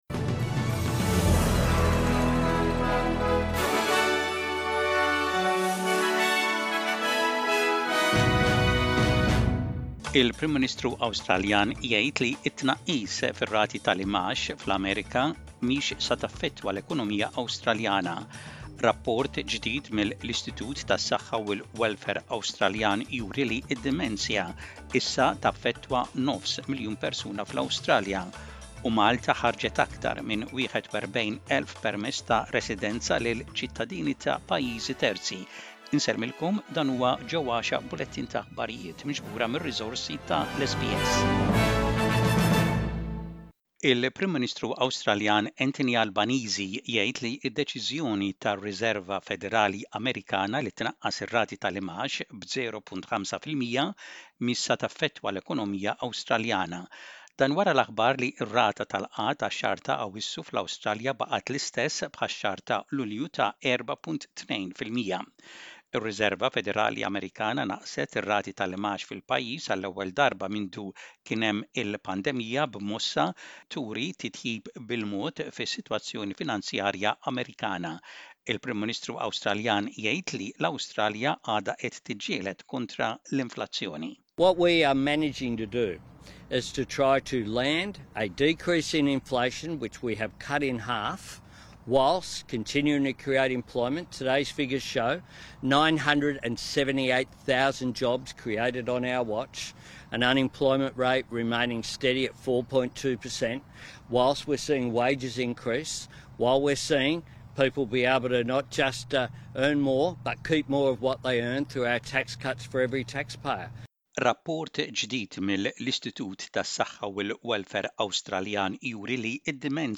SBS Radio | Aħbarijiet bil-Malti: 20.09.24